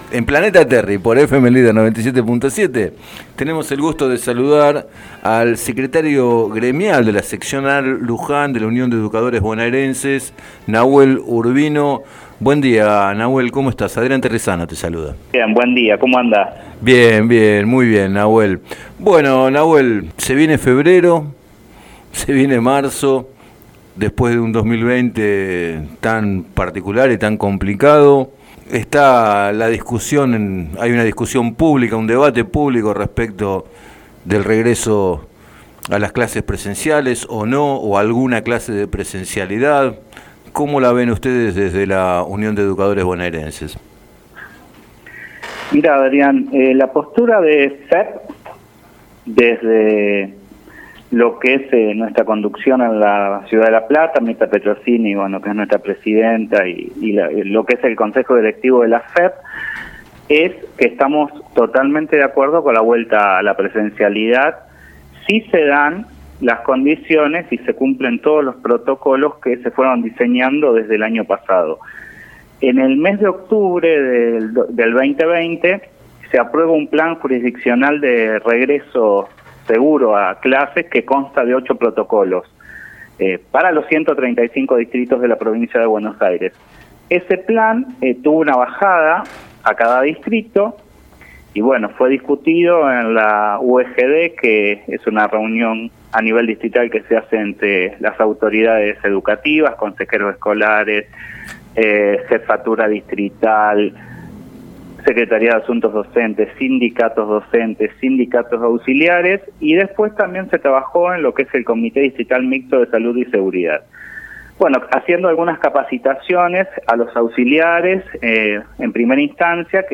En declaraciones al programa Planeta Terri de FM Líder